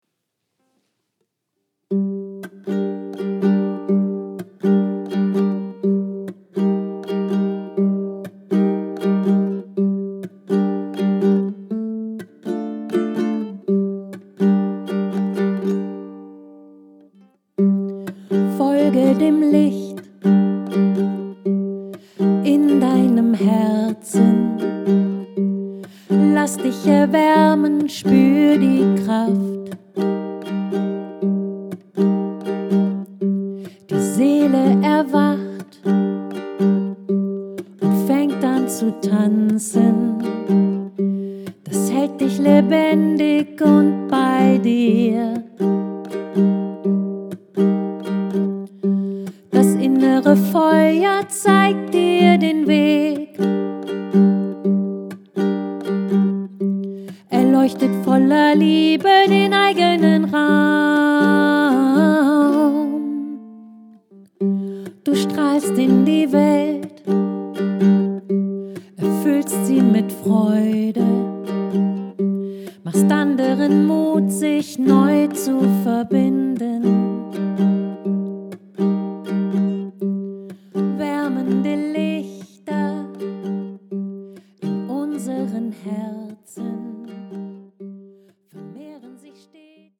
Heilsam klingende Botschaften aus dem Universum.